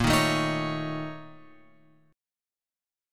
A# Minor Major 7th Double Flat 5th